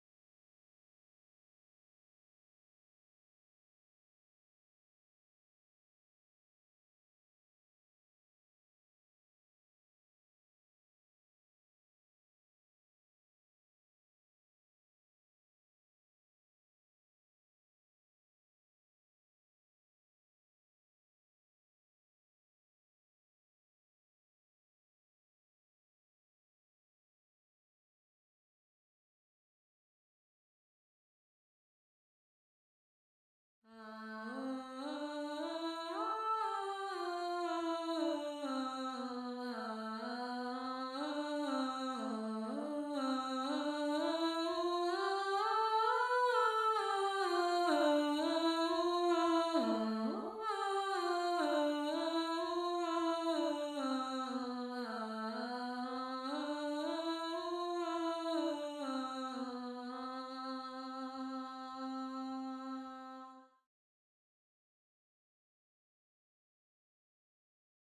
2. ALTO (Alto/Alto)